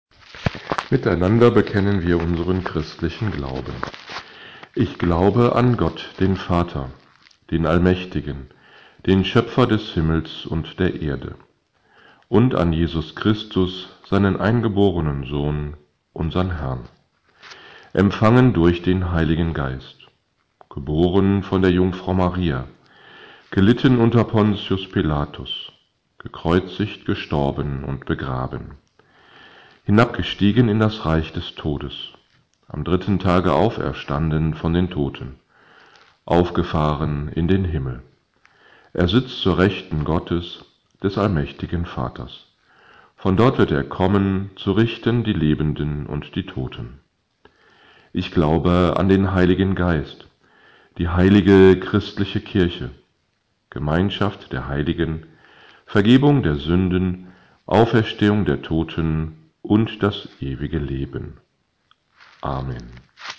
Audio-Gottesdienst zum 2. Sonntag der PassionszeitReminszere, 28 Februar 2021